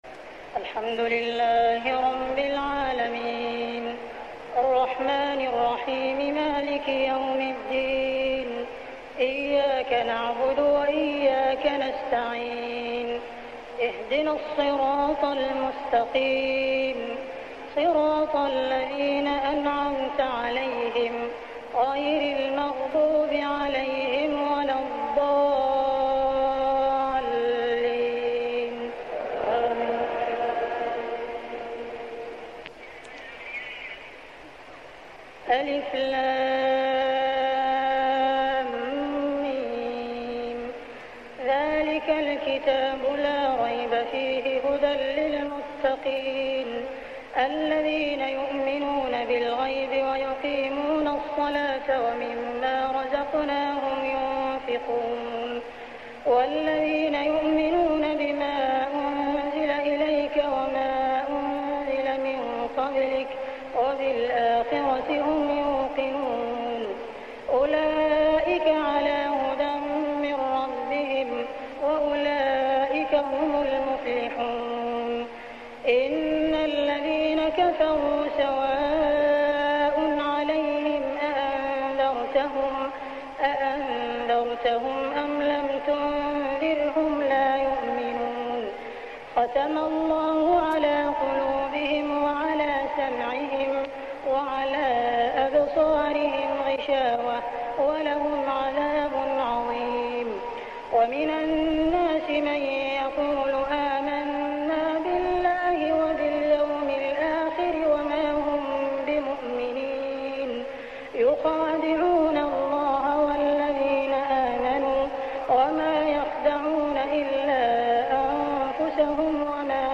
صلاة التراويح ليلة 2-9-1407هـ سورتي الفاتحة كاملة و البقرة 1-74 | Tarawih Prayer Surah Al-Fatihah and Al-Baqarah > تراويح الحرم المكي عام 1407 🕋 > التراويح - تلاوات الحرمين